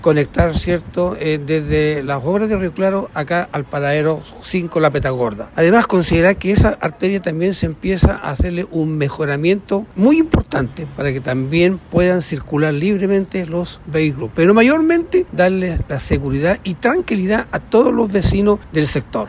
En este sentido, el alcalde de la comuna, José Saez Vinet, se refirió a este importante adelanto para el territorio.